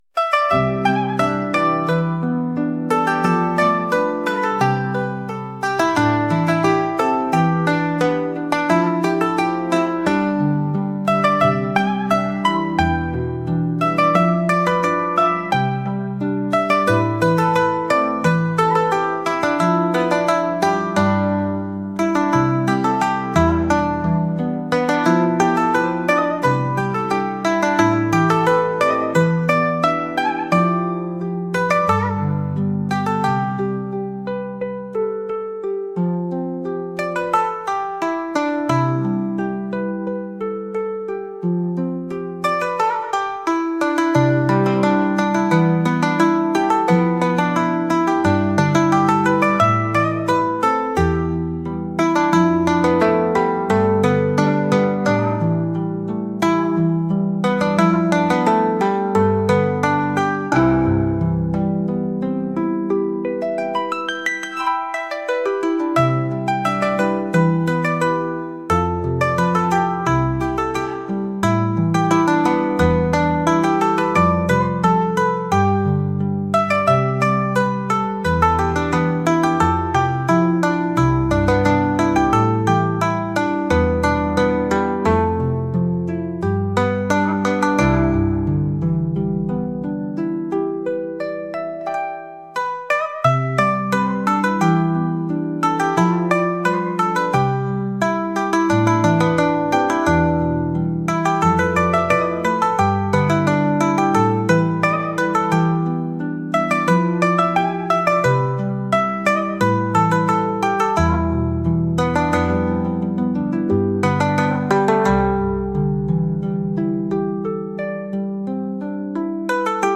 春がもうすぐ訪れるような中華っぽい曲です。